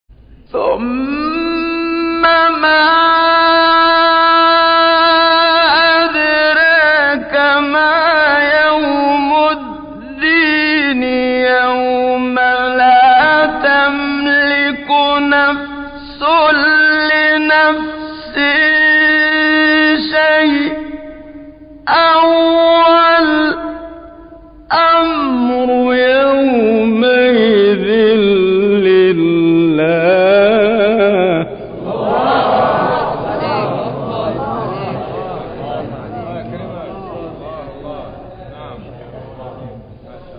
گروه شبکه اجتماعی: فرازهایی از تلاوت قاریان ممتاز مصری در زیر ارائه می‌شود.